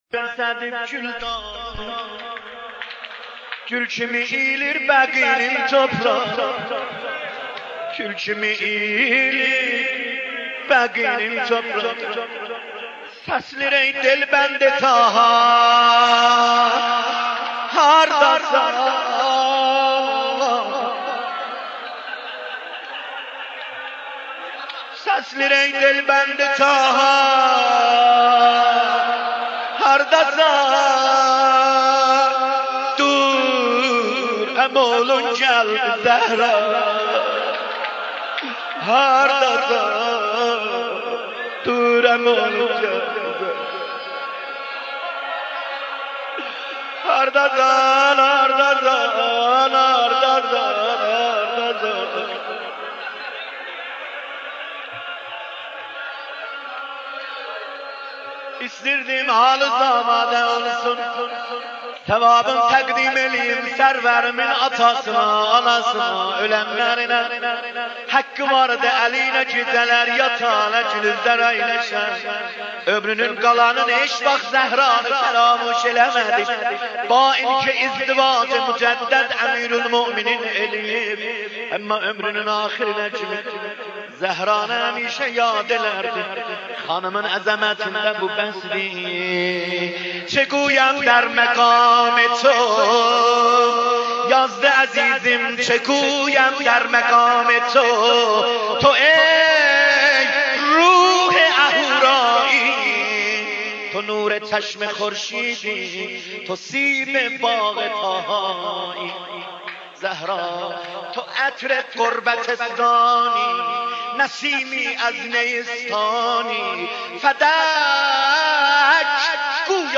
دانلود مداحی به یاد زهرای شهیده - دانلود ریمیکس و آهنگ جدید